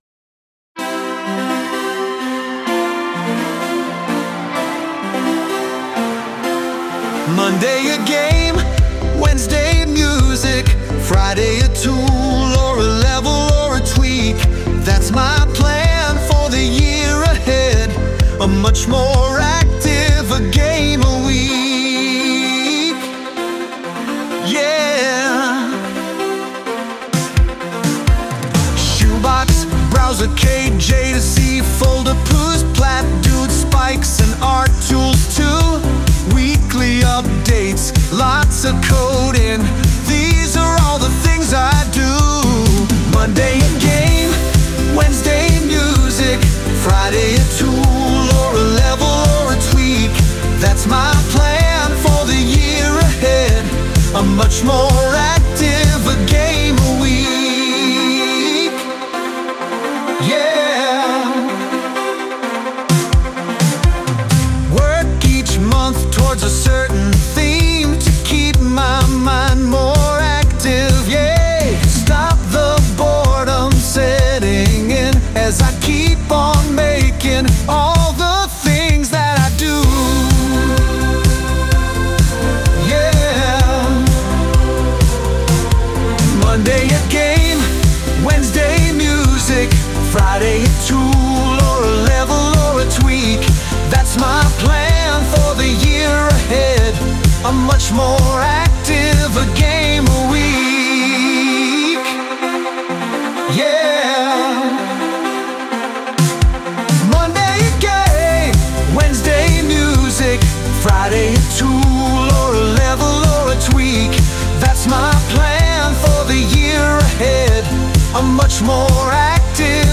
Europop version